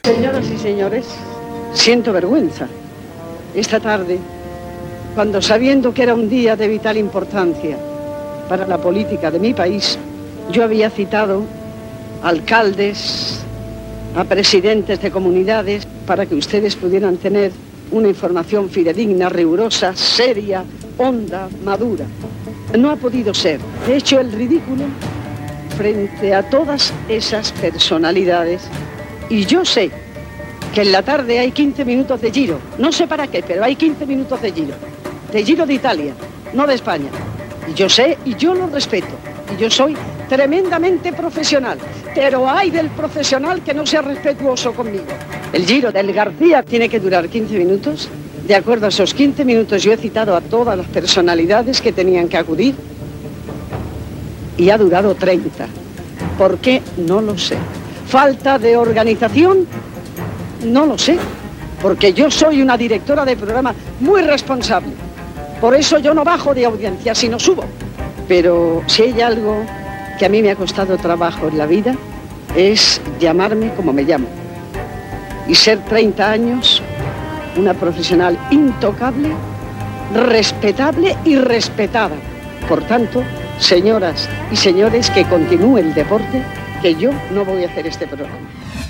Encarna Sánchez en la presentació del programa es queixa perquè José María García s'ha passat del temps en la transmissió del "Giro de Italia".
Info-entreteniment